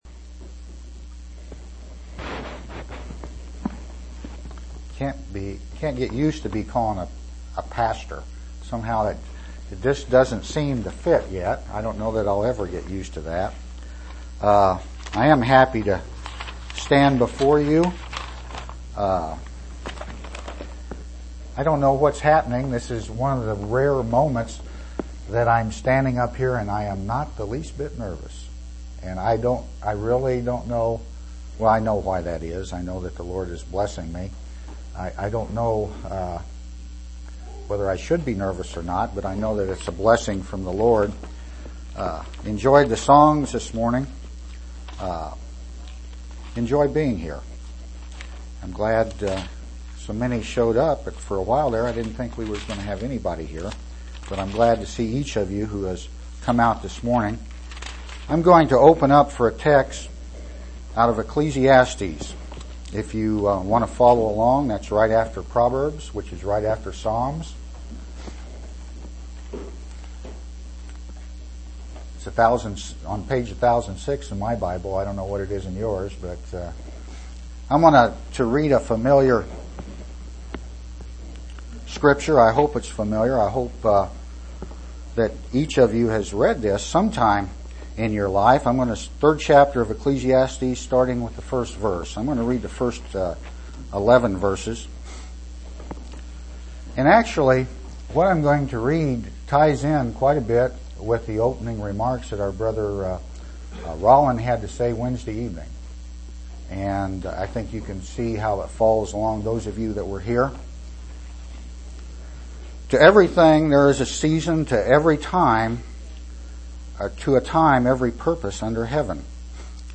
10/24/1999 Location: East Independence Local Event